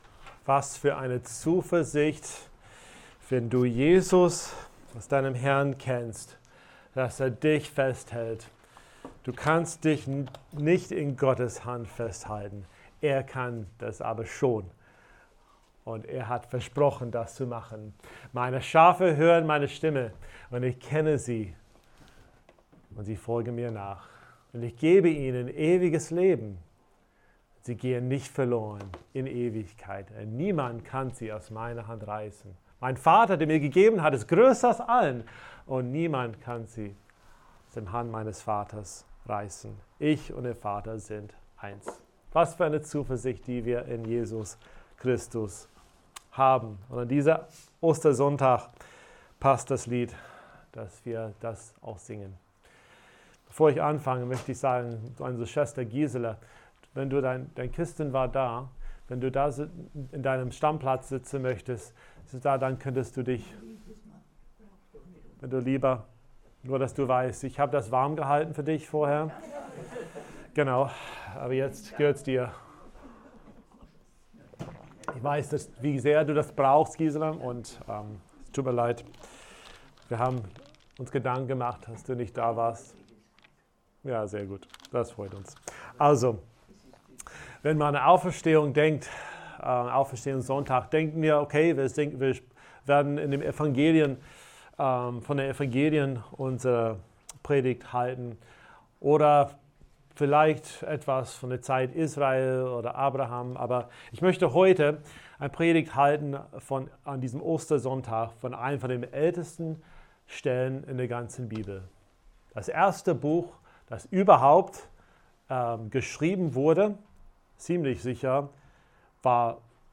Gottesdienst am 12.07.2020
Predigten